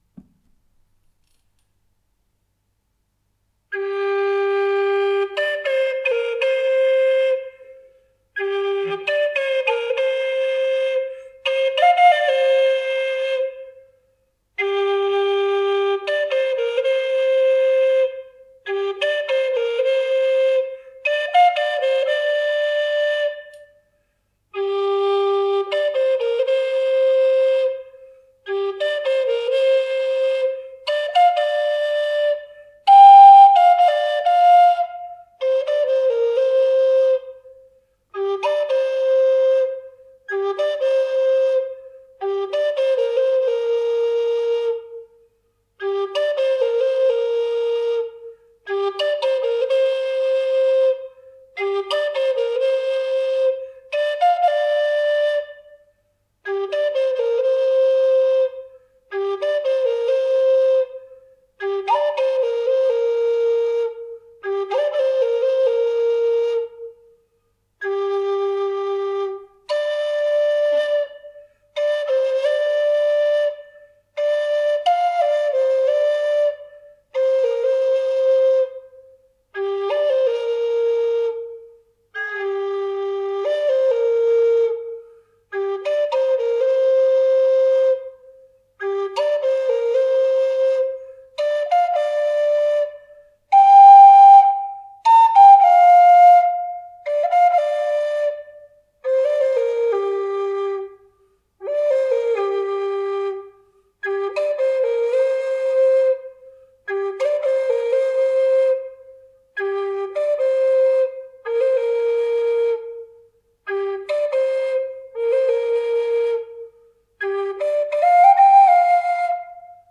a few melodies (in G)